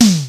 Index of /90_sSampleCDs/300 Drum Machines/Akai XR-10/Snaredrums
Snaredrum-03.wav